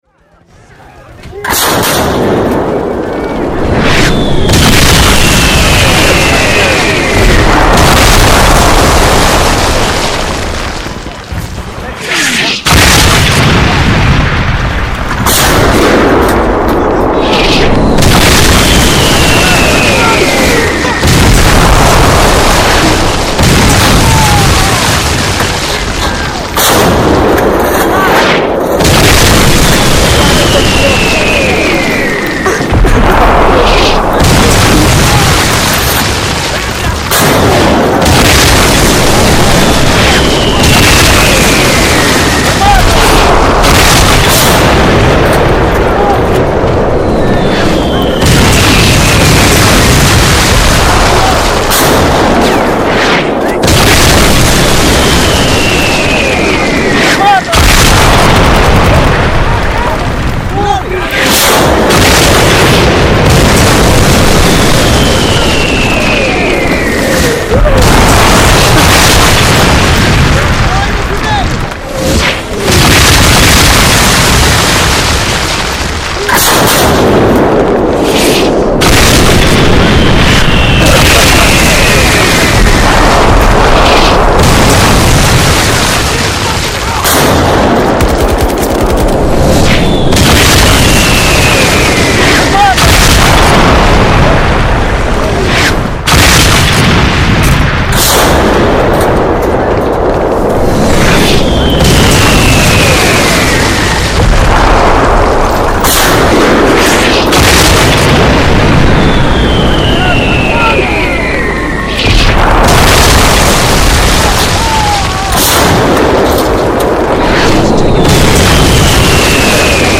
MKiwtGpR6K3_Artillería---Efecto-de-sonido.mp3